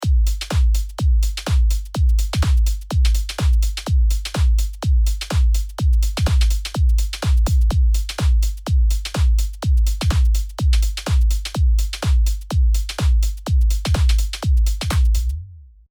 まず、何もかかっていない音